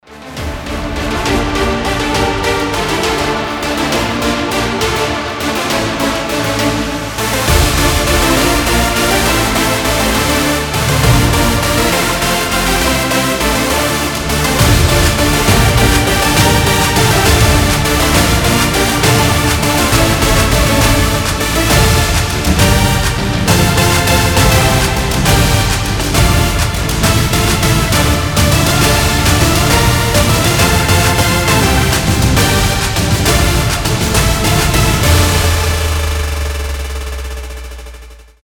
громкие
EDM
Big Room